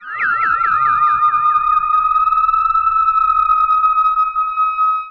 Theremin_Swoop_08.wav